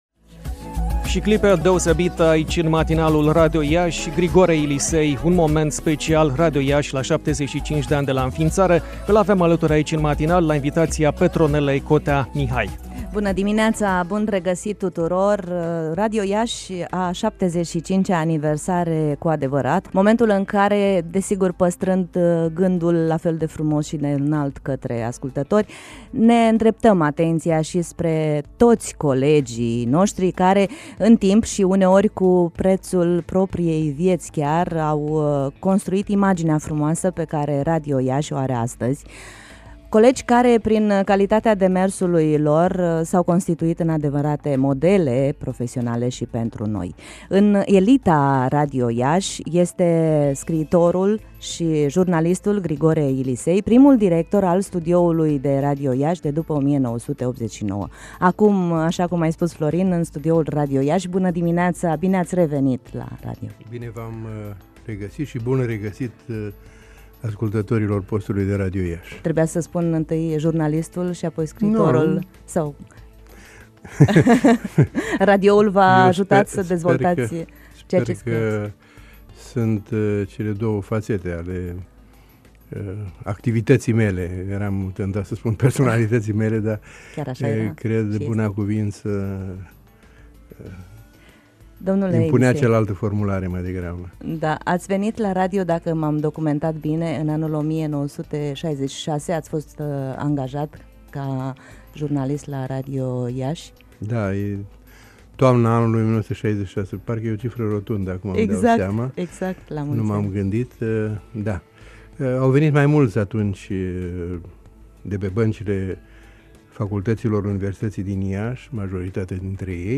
RADIO IAȘI – 75 DE ANI DE LA ÎNFIINȚARE (2 noiembrie 1941 – 2 noiembrie 2016) Dialog, la microfon